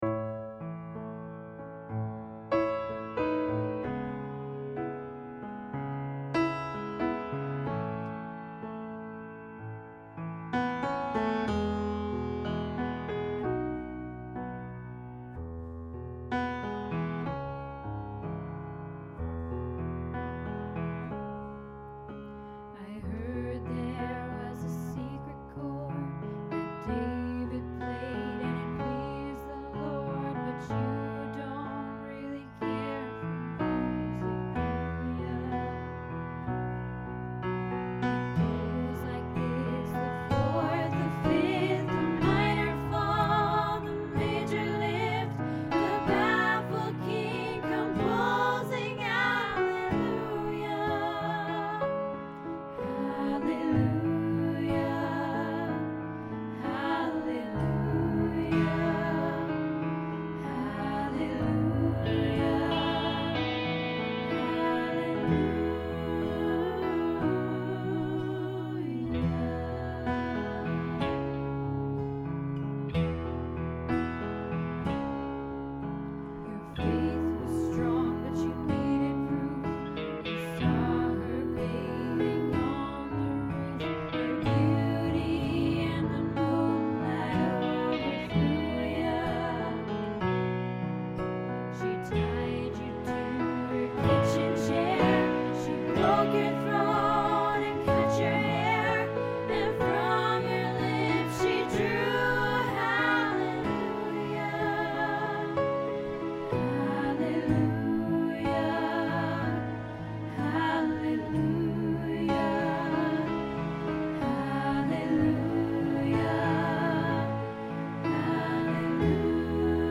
Hallelujah Tenor